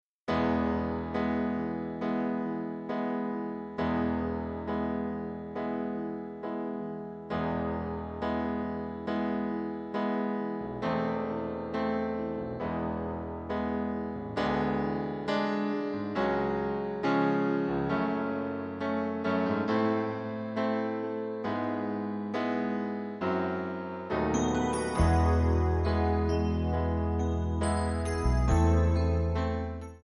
Buy Without Backing Vocals
Backing track Karaoke
Pop, 1990s